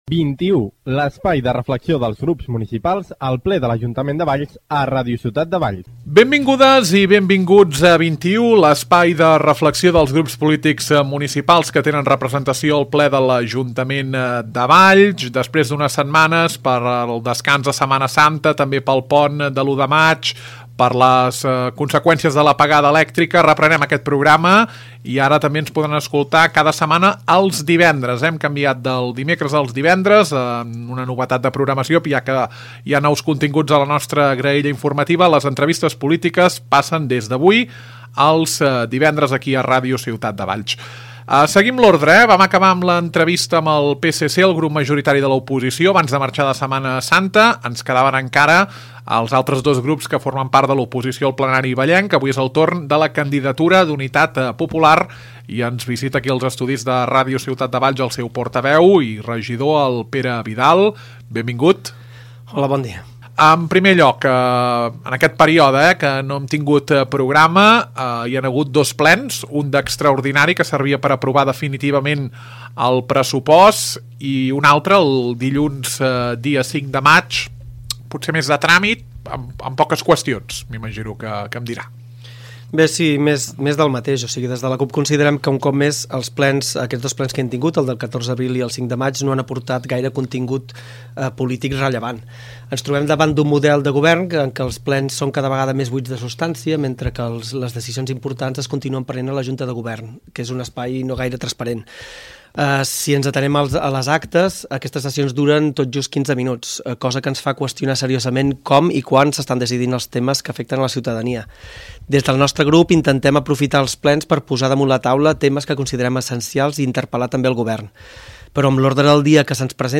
Parlem amb el regidor, Pere Vidal.